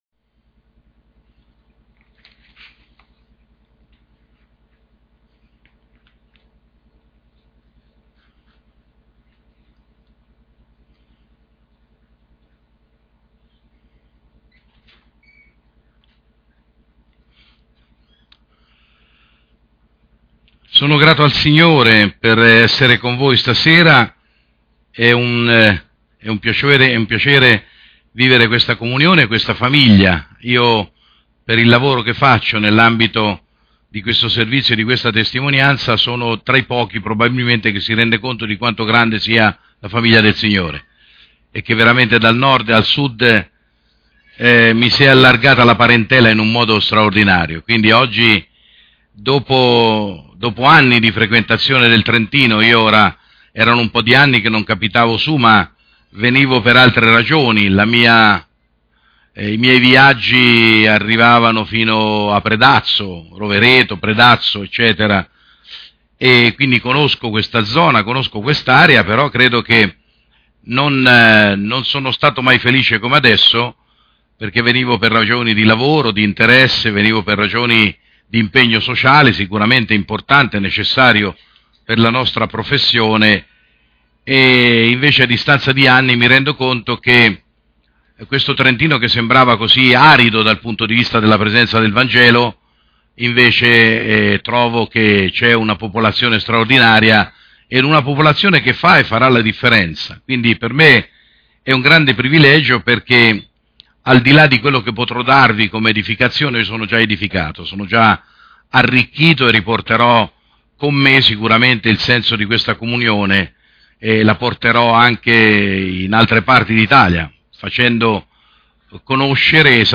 Home » Predicazioni » L’unità dei credenti